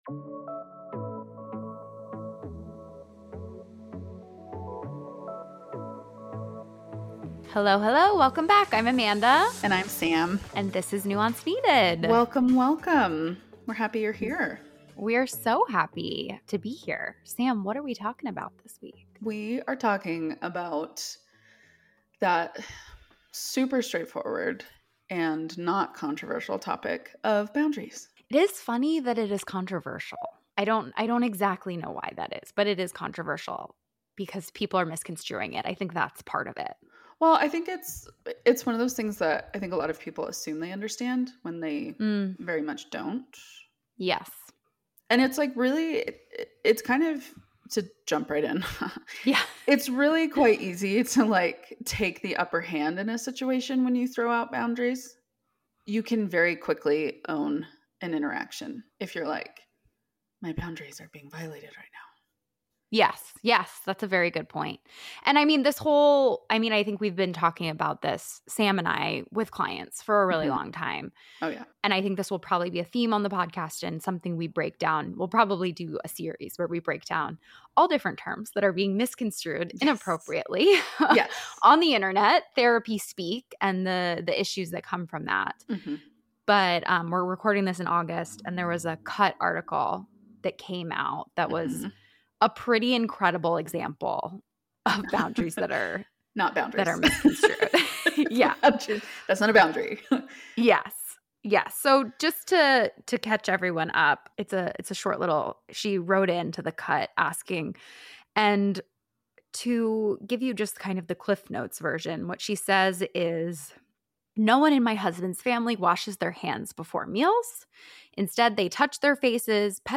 The conversation also touches on the impact versus harm debate and the role of repair in building trust and understanding.